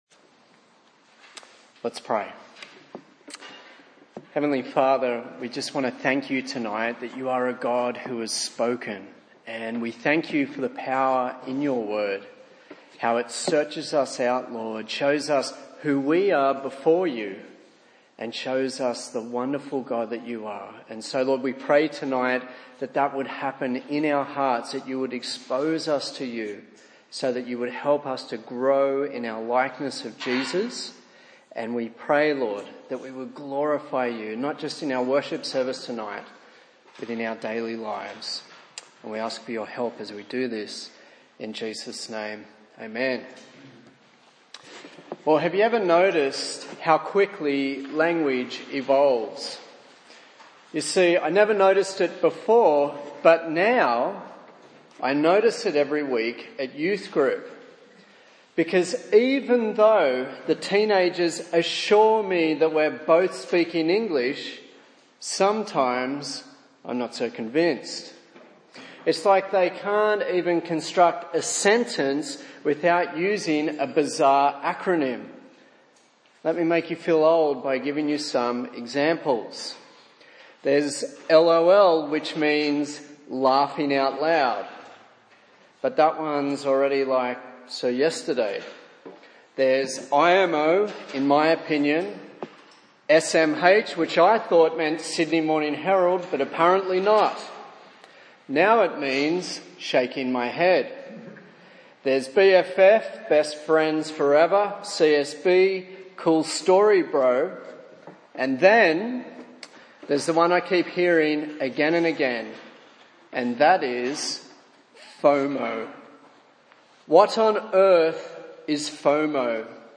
A sermon in the series on the book of Hebrews